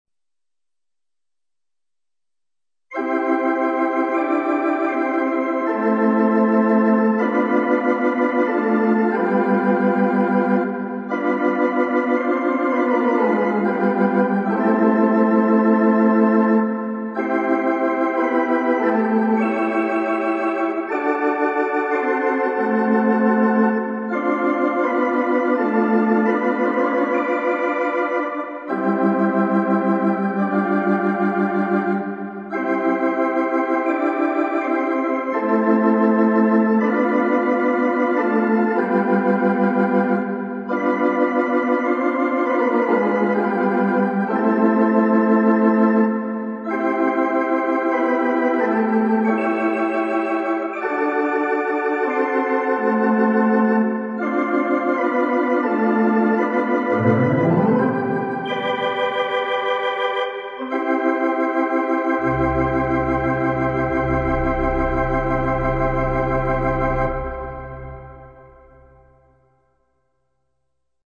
The Miditzer, A MIDI Theatre Organ
Moonbeams is arranged in block chord style.  The left and right hands both play the melody an octave apart on the Solo manual and the right hand adds the harmony in between the two melody notes.  This is a simple but effective technique for achieving the full theatre organ sound.
I used the SUB coupler to provide some bass.